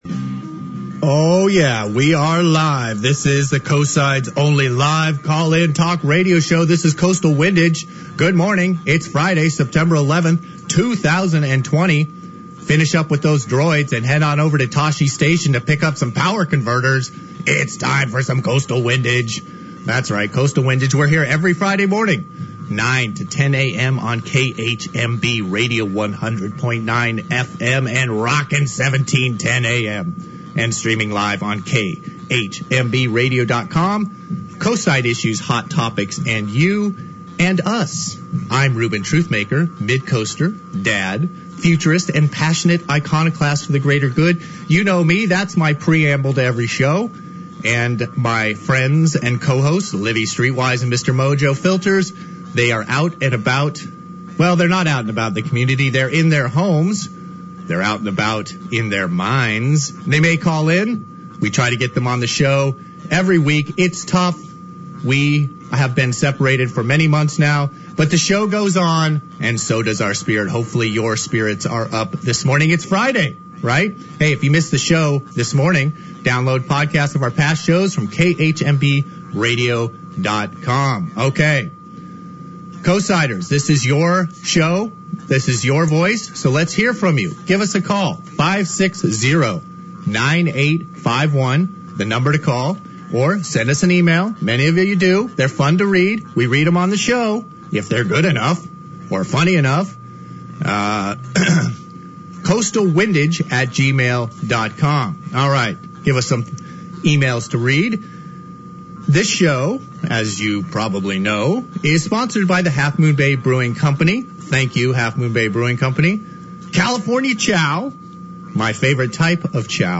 Recorded Friday, Sept. 11th, 2020 by Zoom.
The Coastside’s only live, call-in talk radio show, Coastal Windage features Coastside issues, hot topics, and live comments from Coastsiders in real time.